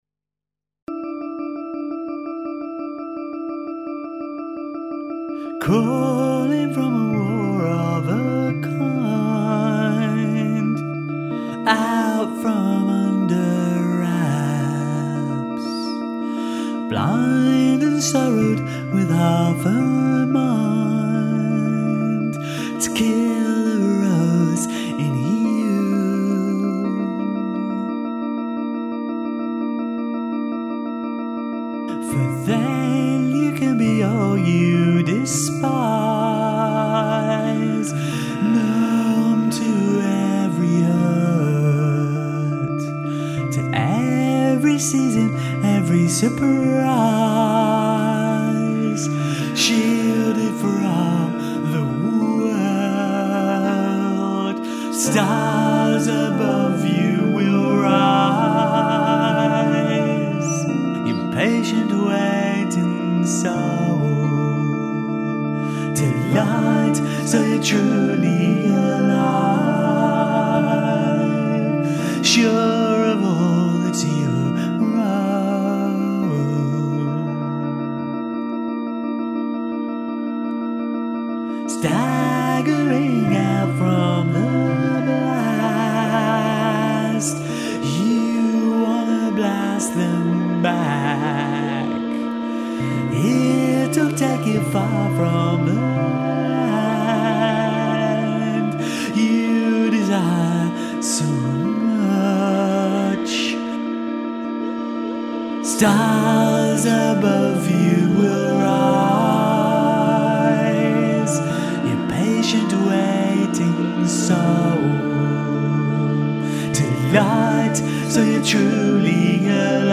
piano, organ, bass, electric Hawaiian guitar
Additional vocals